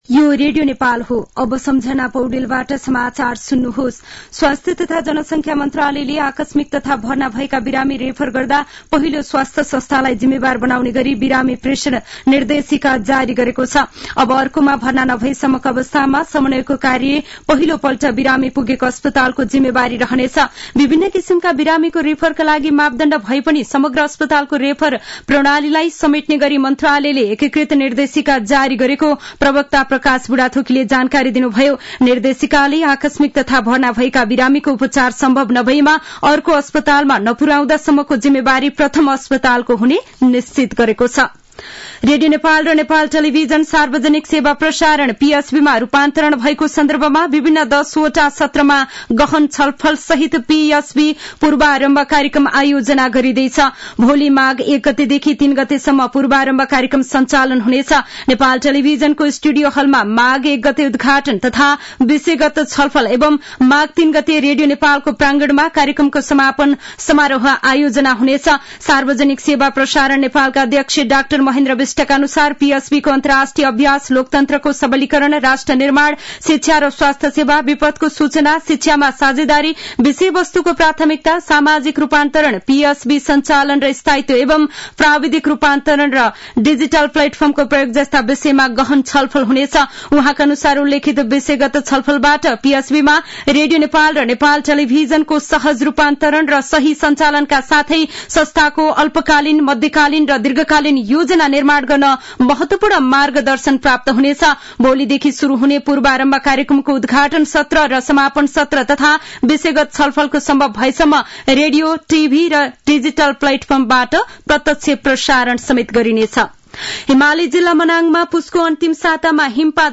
दिउँसो १ बजेको नेपाली समाचार : १ माघ , २०८१
1-pm-news-1-5.mp3